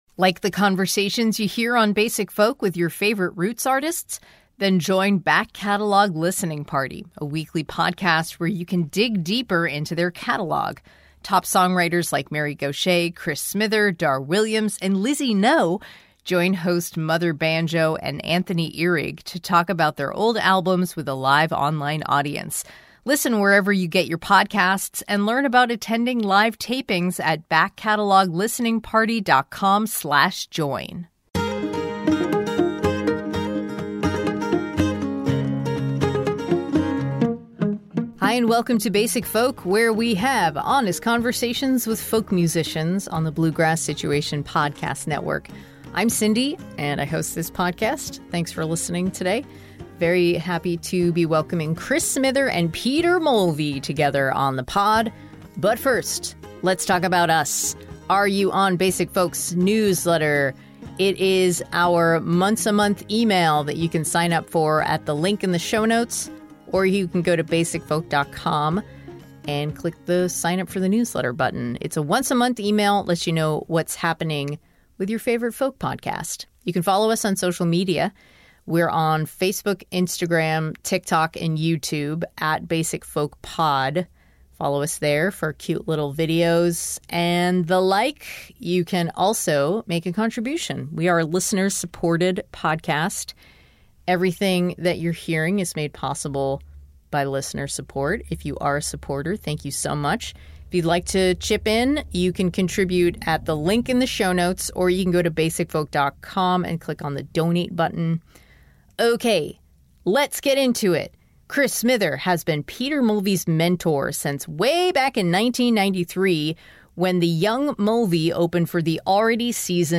LISTEN: APPLE • SPOTIFY • AMAZON • MP3 In this rare joint interview on Basic Folk, we address the important questions: Why do they delight in calling each other by their last names?